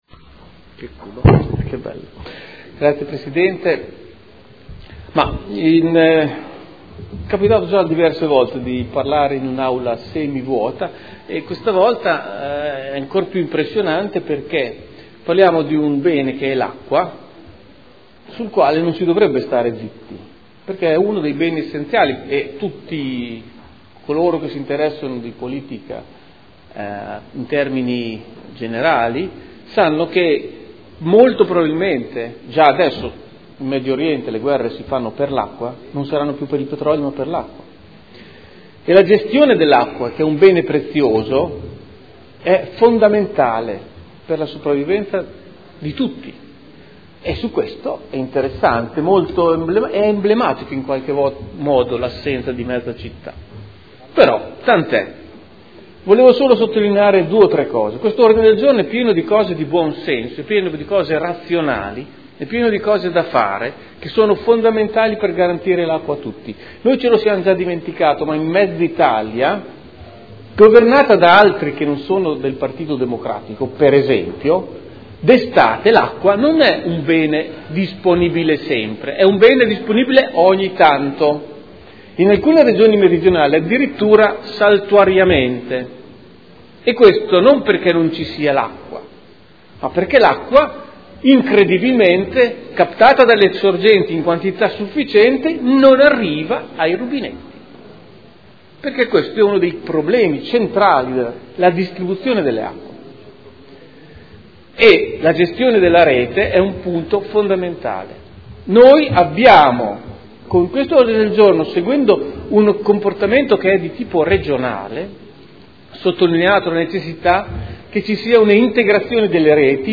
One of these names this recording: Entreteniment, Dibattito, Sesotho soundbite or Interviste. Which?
Dibattito